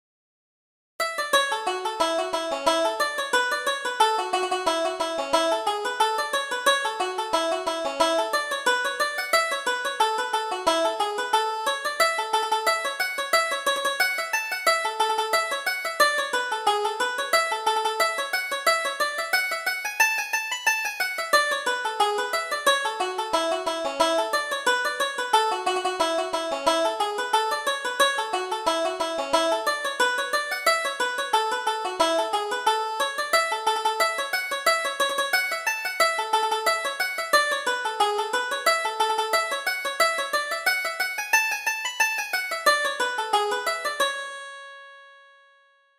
Reel: The First Month of Summer